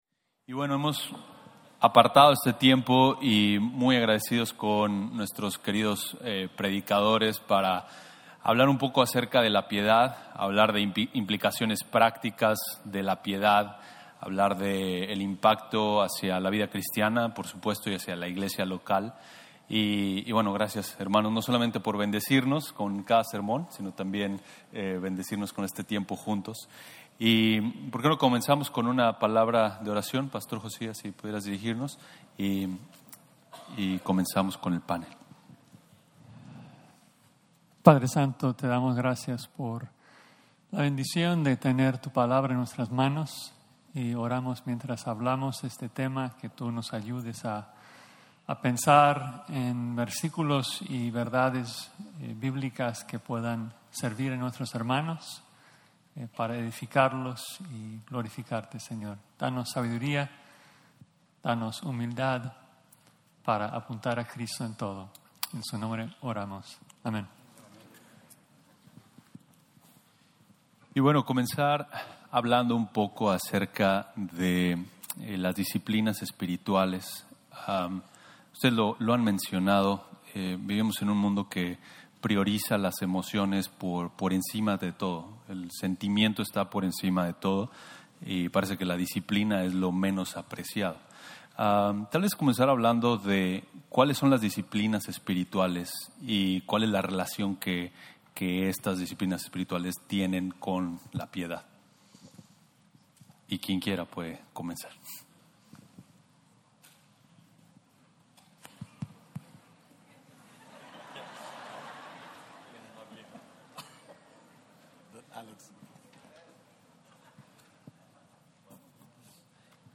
Sesión de preguntas y respuestas (Spanish)